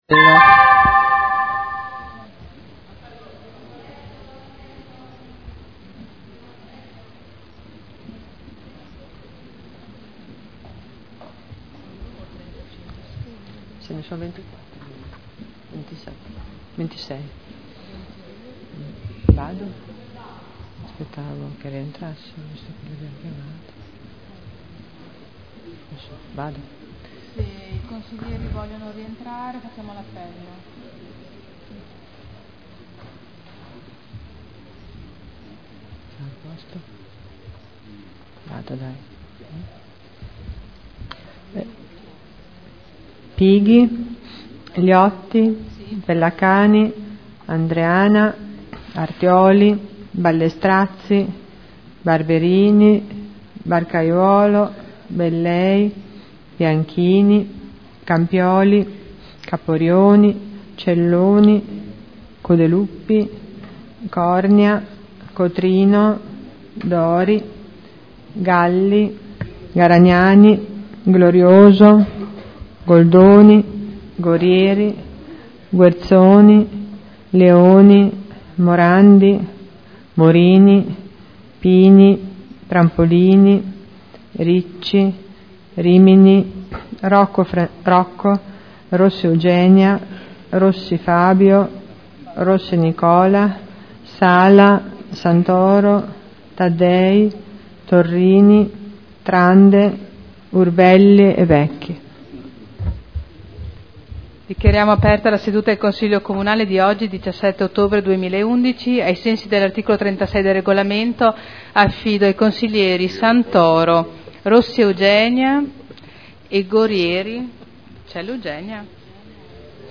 Seduta del 17 ottobre Apertura del Consiglio Comunale Appello